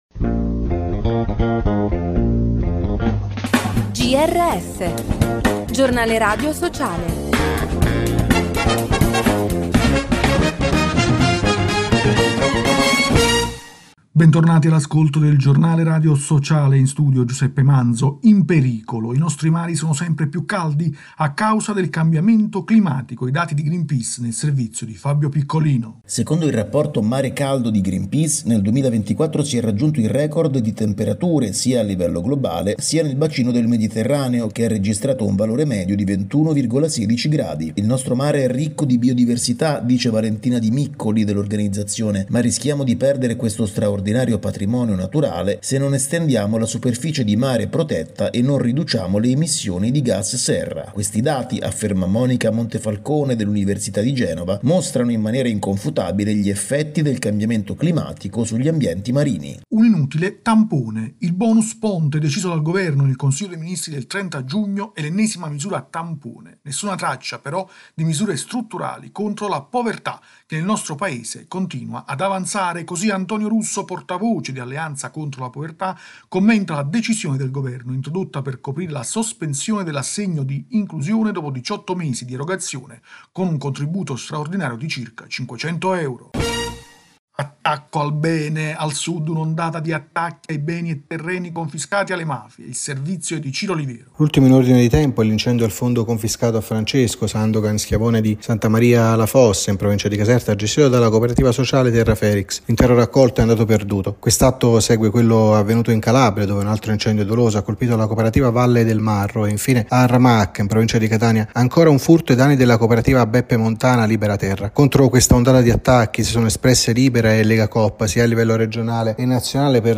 Bentornati all’ascolto del Giornale radio sociale. In studio